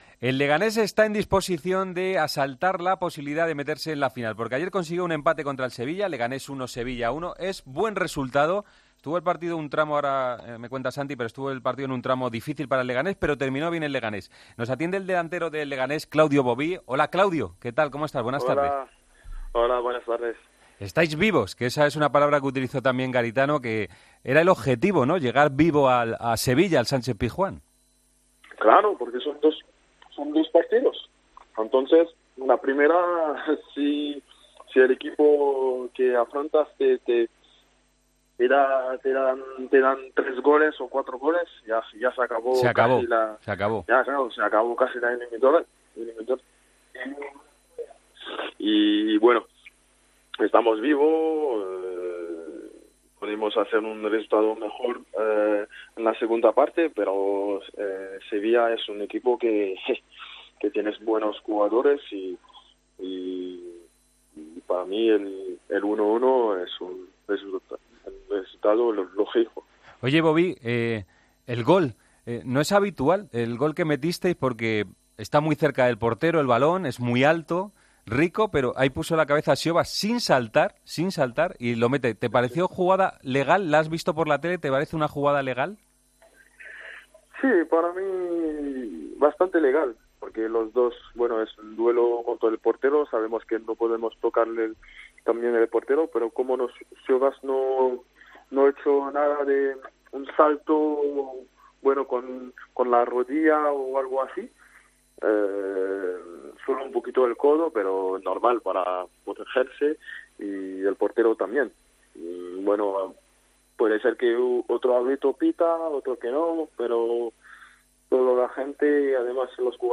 Preguntamos al delantero del Leganés por el partido de ida ante el Sevilla. Beauvue cuenta que le encanta cantar, explica cómo es su Guadalupe natal y que ganó la Copa de Francia con el Guingamp. Sobre el PSG-Madrid apunta que ve "la eliminatoria al cincuenta por ciento".